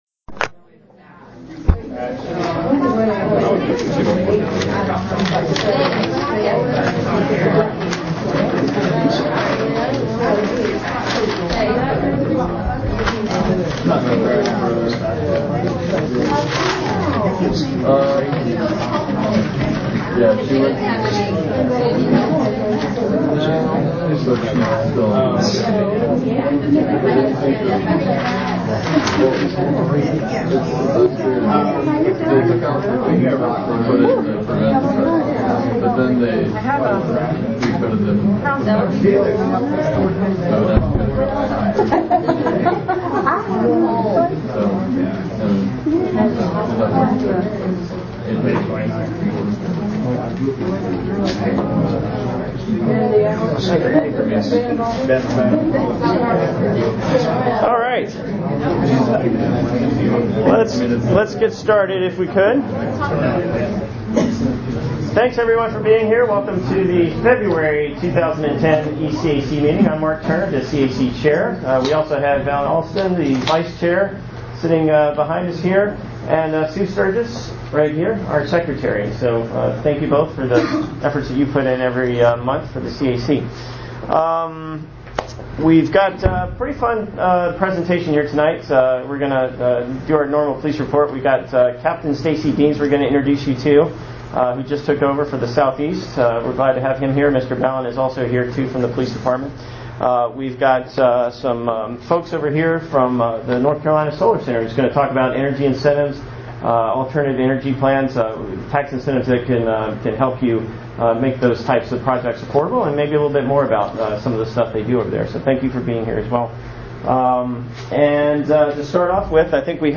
Note that due to technical difficulties, a portion of the meeting was not recorded. This split is represented by a beep in the audio.